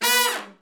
Index of /90_sSampleCDs/Roland L-CDX-03 Disk 2/BRS_R&R Horns/BRS_R&R Falls